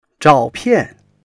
zhao4pian4.mp3